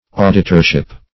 Auditorship \Au"di*tor*ship\, n. The office or function of auditor.
auditorship.mp3